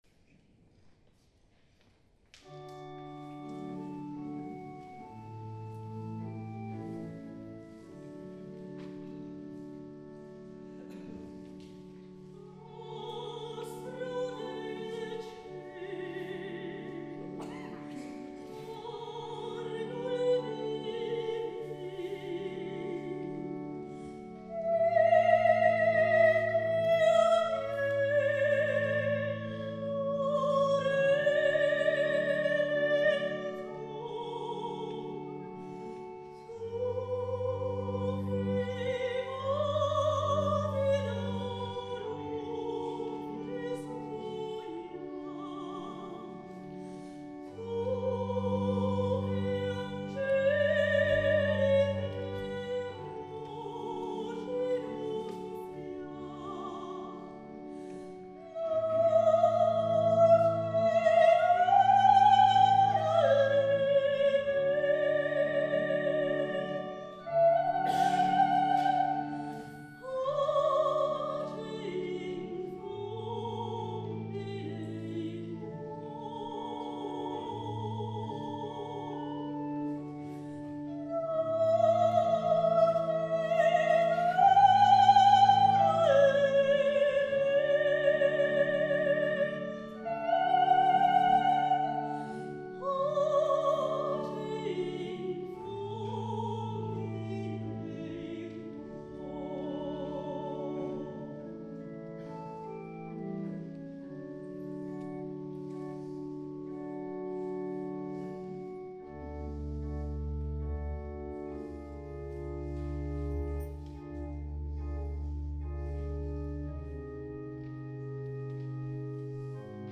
S. Gaudenzio church choir Gambolo' (PV) Italy
Pieve di Sant'Eusebio
CONCERTO DI NATALE